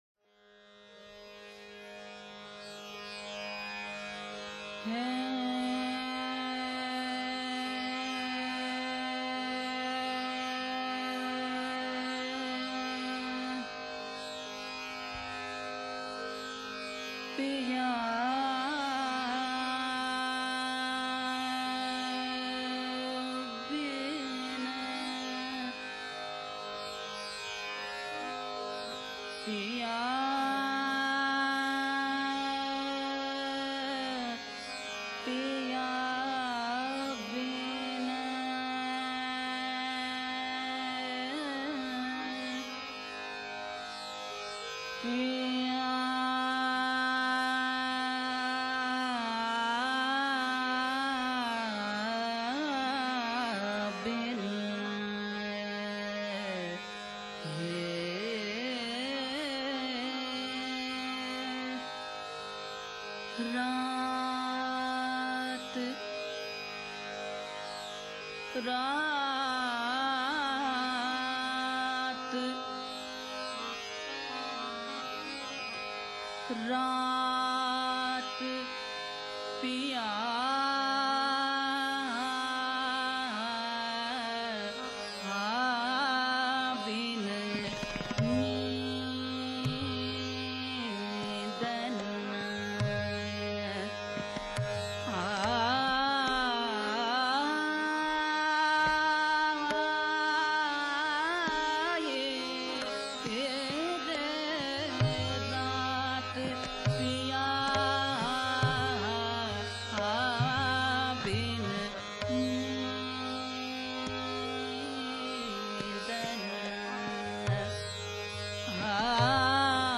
Tabla
Harmonium